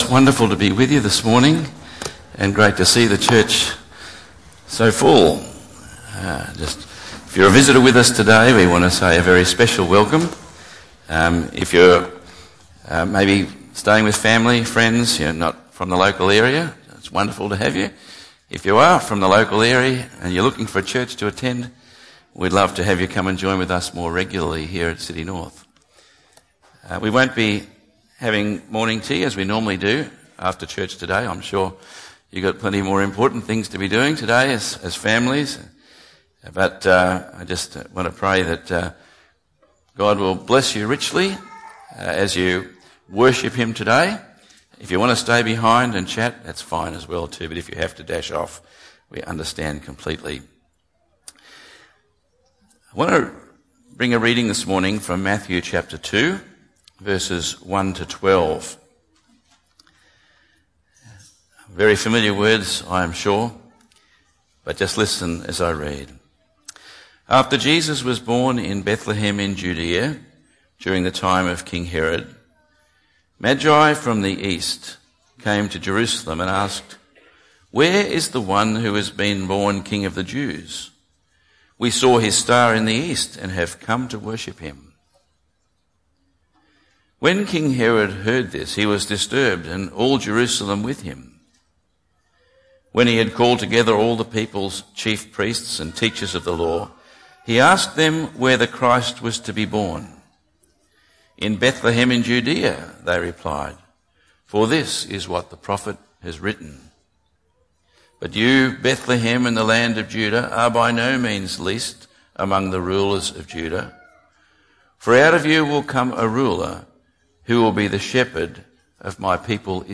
On this Christmas Day 2015 are we wise enough to look for, believe in, and worship Jesus too?